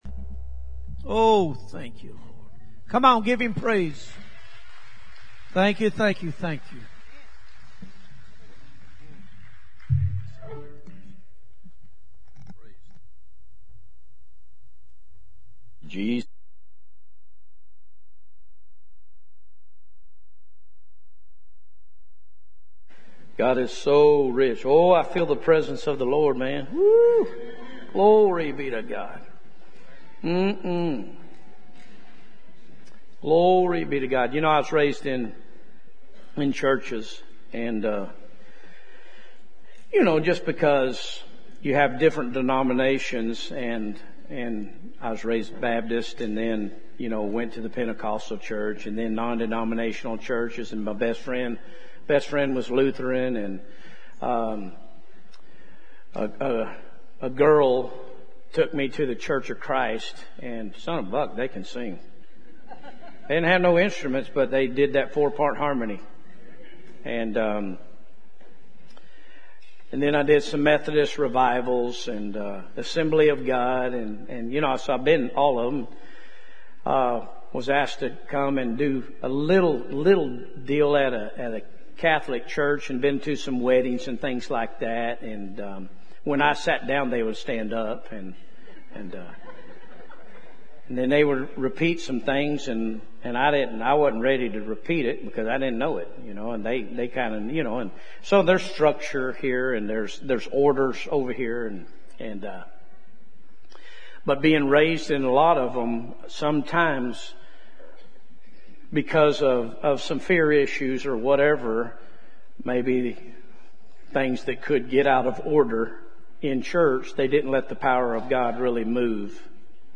Jul 26, 2020 | by Guest Preacher